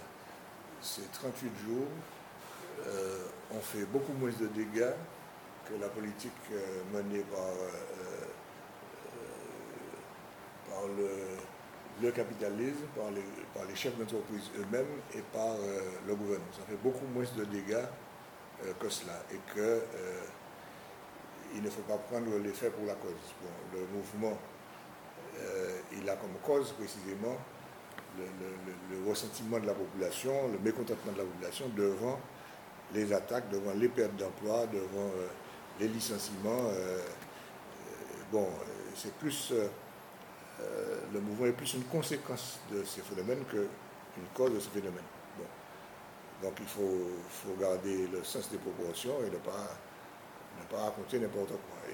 Interview à lire et à écouter.